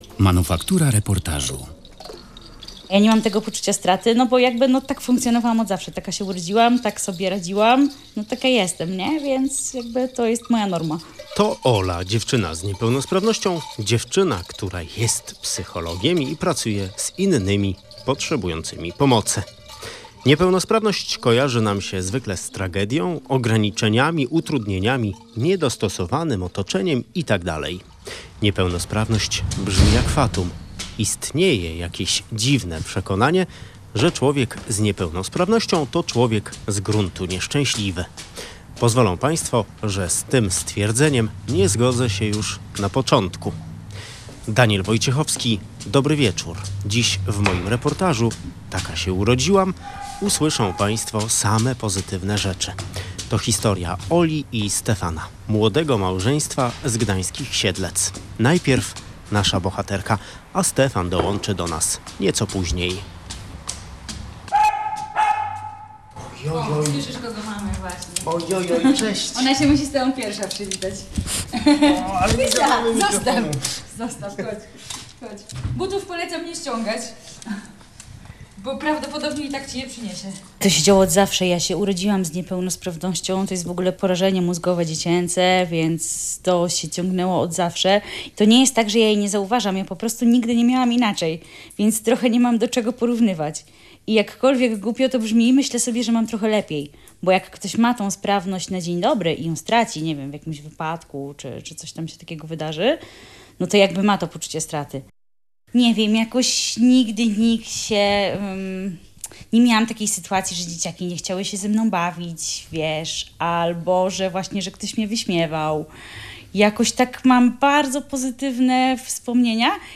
Zapraszamy na reportaż "Taka się urodziłam" - Radio Gdańsk